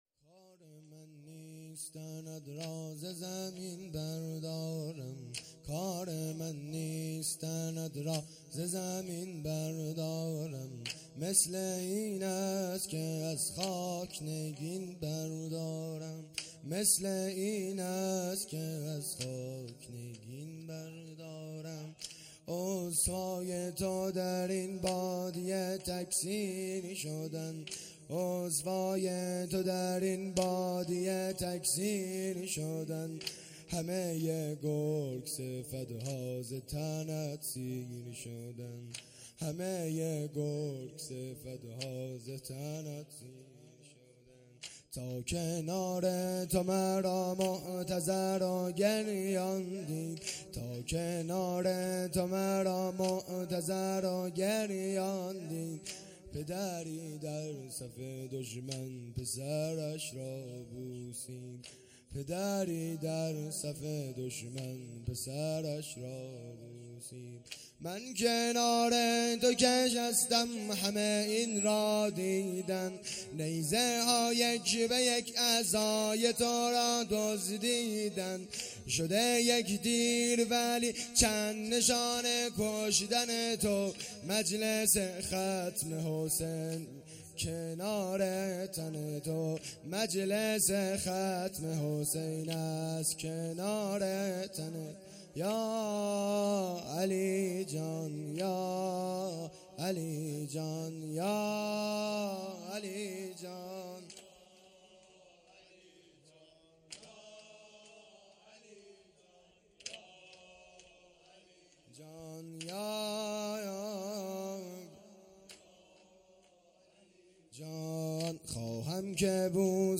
شب هشتم محرم الحرام ۱۴۴۳